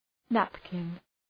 Προφορά
{‘næpkın}